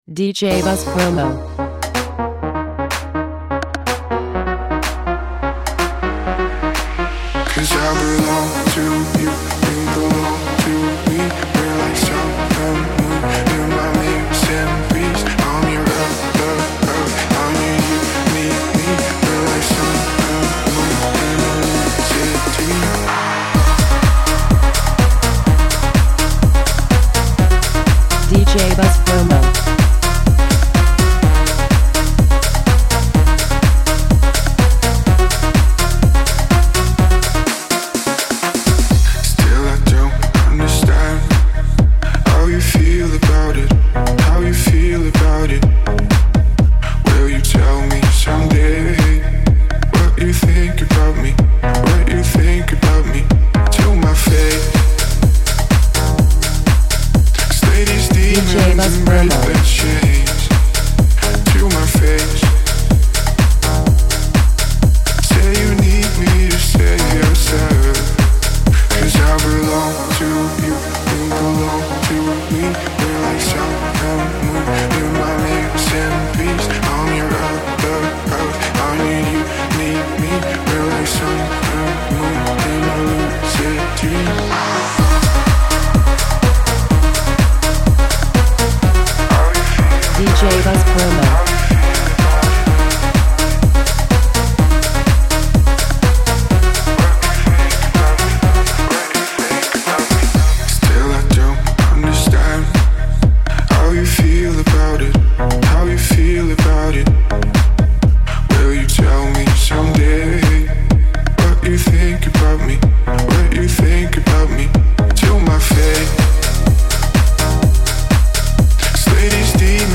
the Italian duo